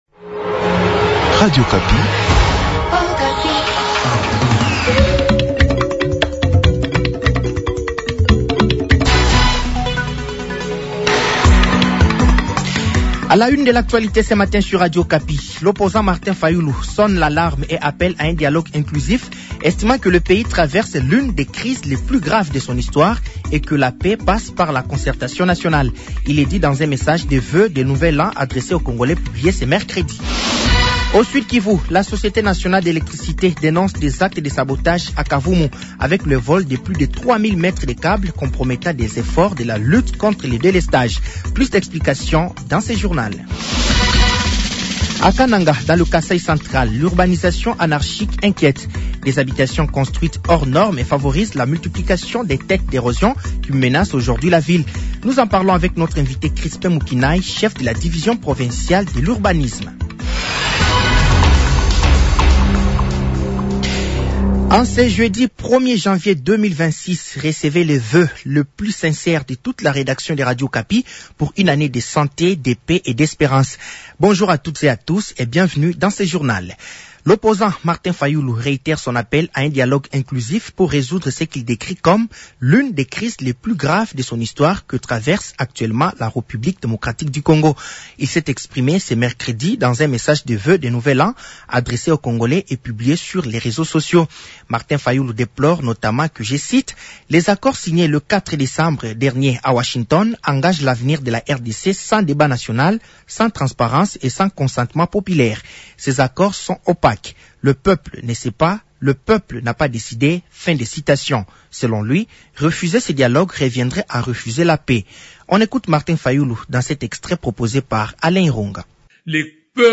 Journal français de 07h de ce jeudi 1er janvier 2026